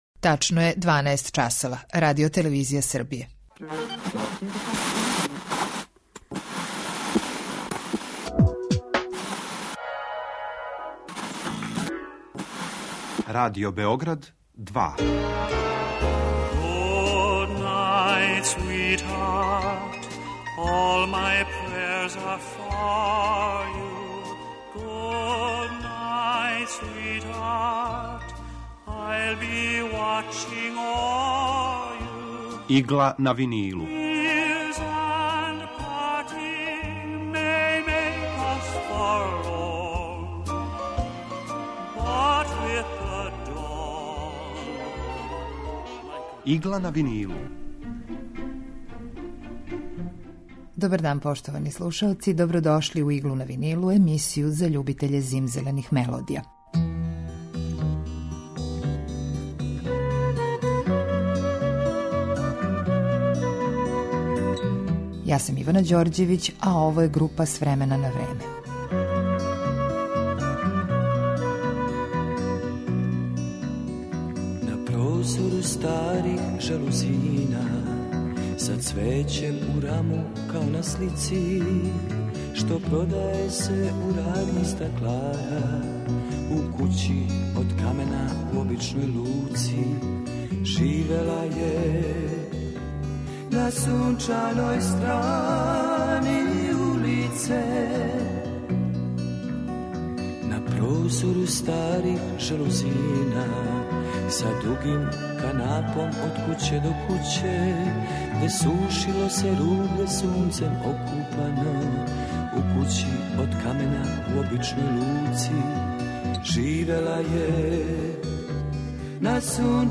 Емисија евергрин музике